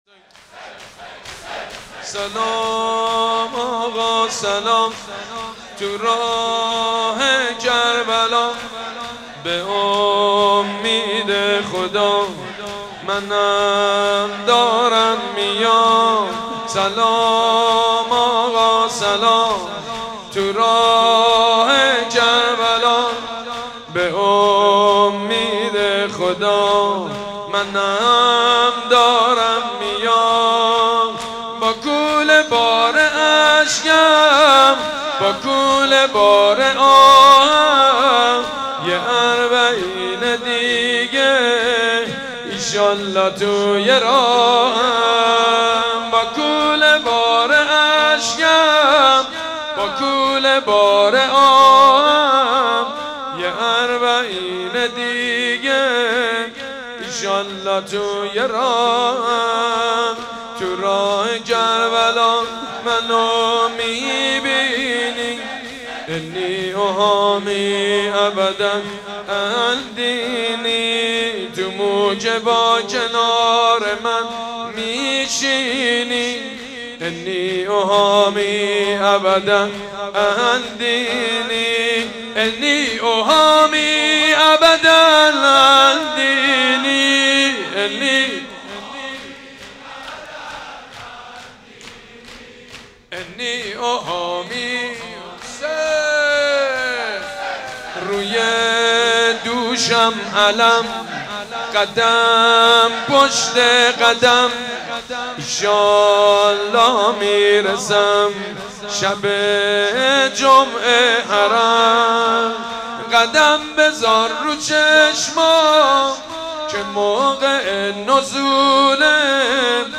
شور اربعین